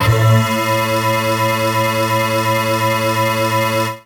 55bg-syn10-a2.wav